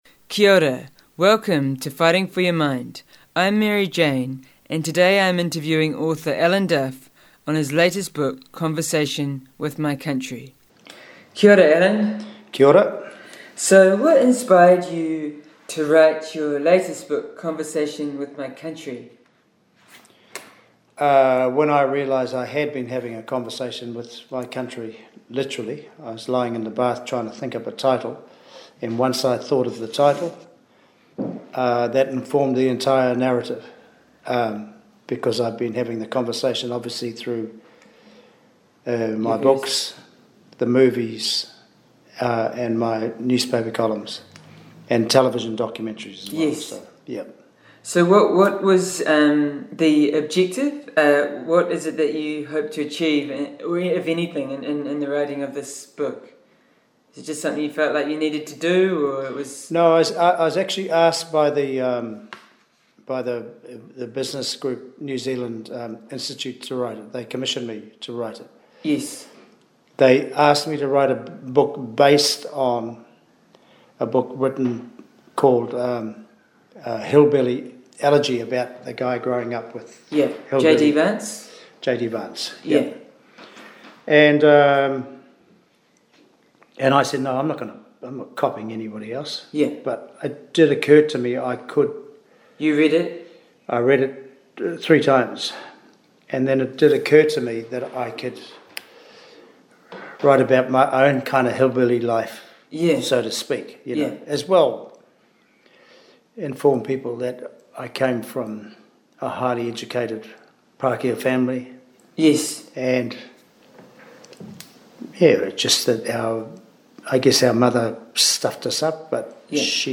Alan-Duff-Interview-9th-edit-with-intro-and-outro.mp3